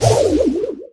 mrp_dryfire_01.ogg